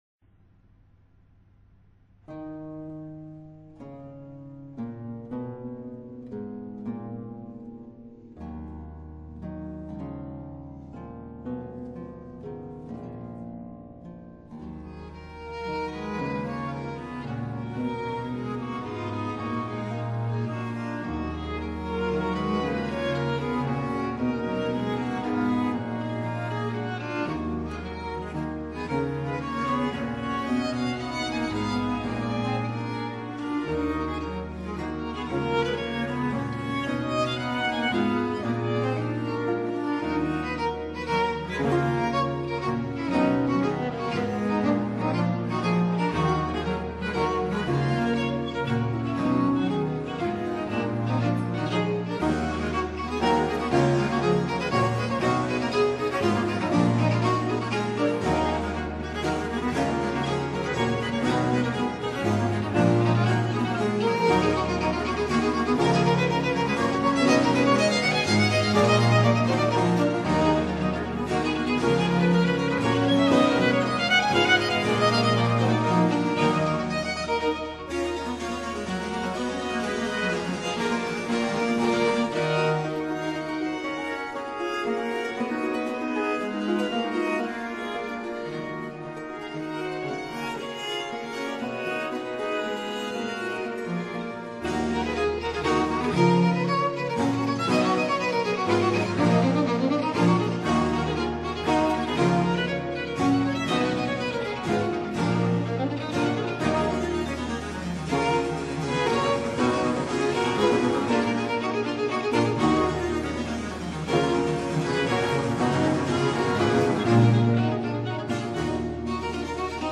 Herr, wenn ich nur dich hab Dietrich Buxtehude- Ciaccona per organo -160 Stylus Phantasticus- ***Franz Hals Sonata III op.secunda en sol BuxWv 261 Allegro – Lento ( Largo) D. Buxtehude - Quemadmodum Desiderat Cervus